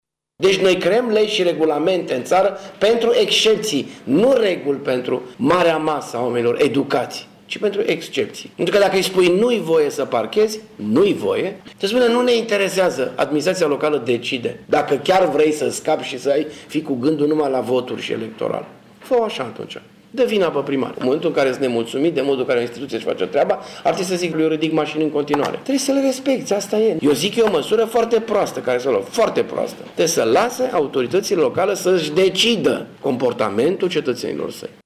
Dorin Florea a declarat, azi, într-o conferinta de presă, că legea în România este făcută pentru excepţii, dar că decizia trebuie respectată: